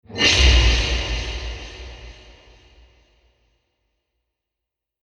Âm thanh Dội, Vang Vọng ở đường hầm tối
Thể loại: Âm thanh hung dữ ghê sợ
Description: Hiệu ứng âm thanh này mô phỏng tiếng vang vọng và dội âm đặc trưng khi âm thanh bị phản xạ trong không gian đường hầm tối, hang động, hành lang dài, hoặc khu vực kín vắng lặng. Tiếng vang tạo cảm giác lạnh sống lưng, trống trải, rùng rợn, thường được dùng trong các cảnh phim thể loại kinh dị, bí ẩn, hồi hộp, hoặc phiêu lưu khám phá.
am-thanh-doi-vang-vong-o-duong-ham-toi-www_tiengdong_com.mp3